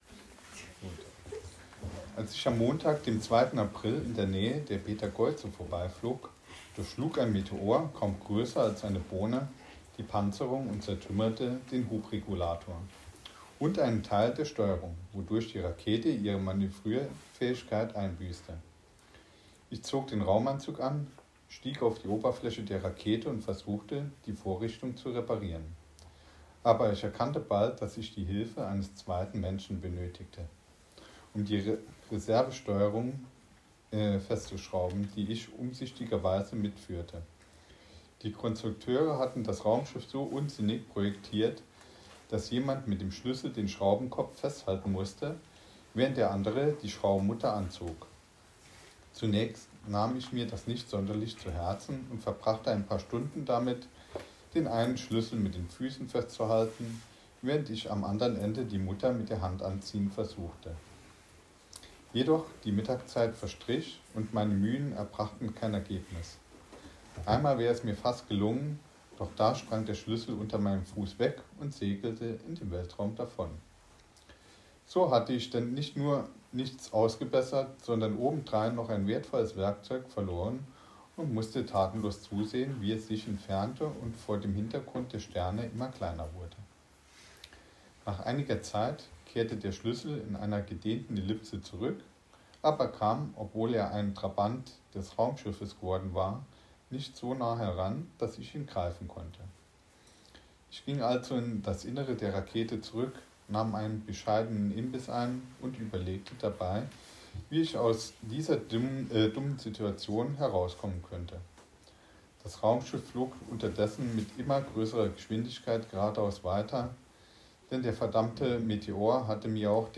Vorlesetag an der Sternwarte - STScI
Zusammen mit dem Bildungsbüro Oberberg beteiligten wir uns am bundesweiten Vorlesetag.
vorlesetag.mp3